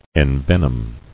[en·ven·om]